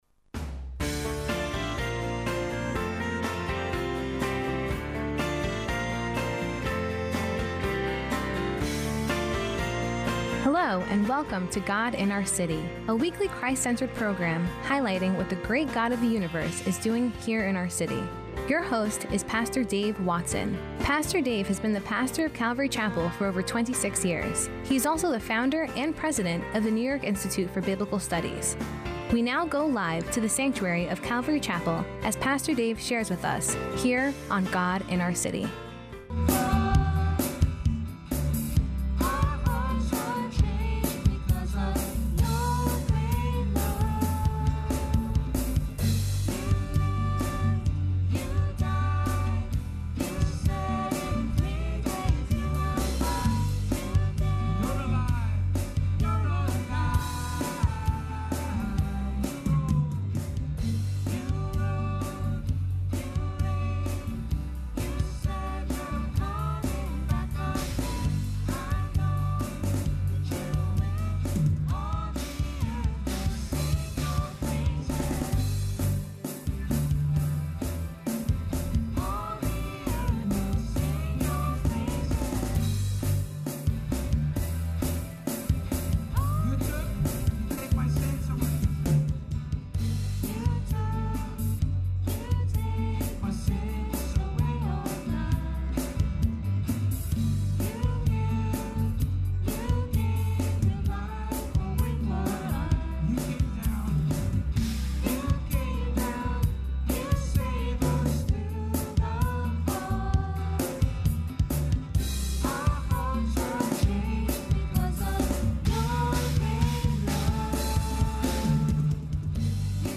Podcast of this week’s “God in Our City” Broadcast -Modern Family Realigned- Pt. 1